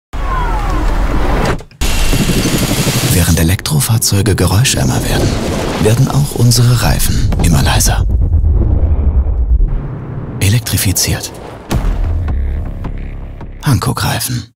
Commercial (Werbung), Off